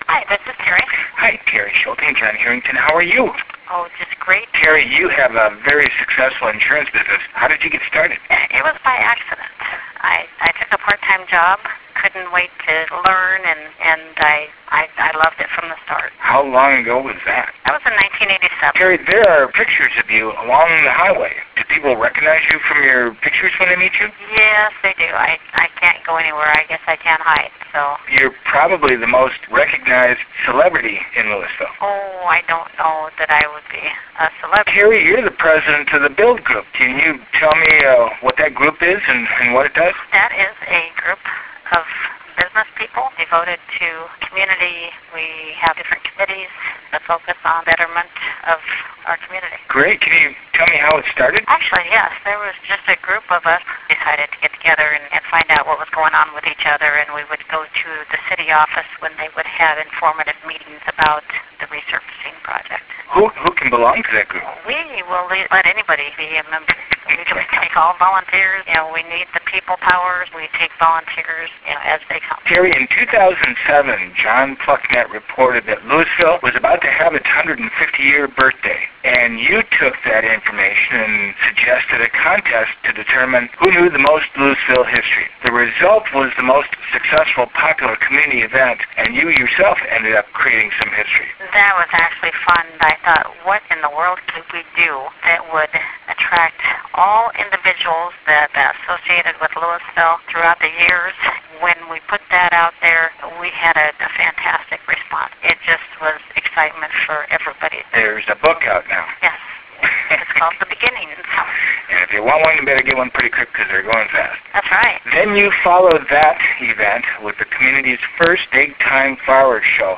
Weekly Newsletter Feature - 3 Minute Interviews
an informal visit with a Local Business Leader